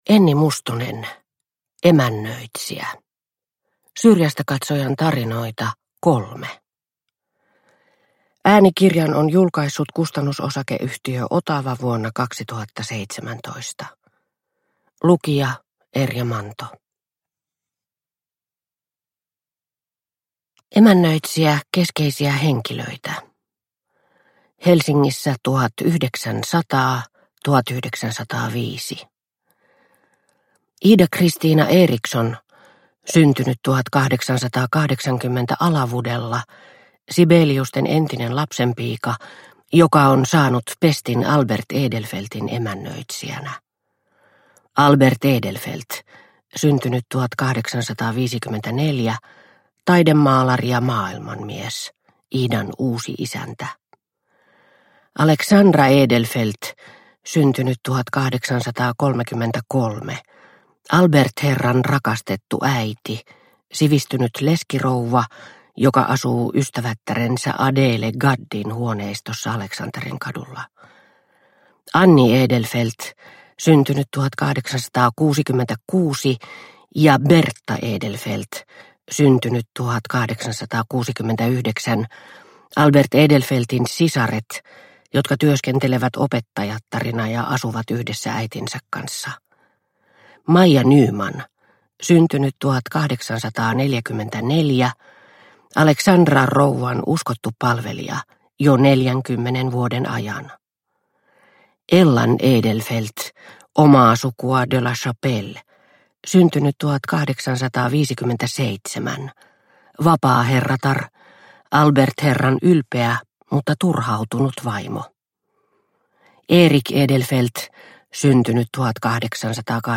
Emännöitsijä – Ljudbok – Laddas ner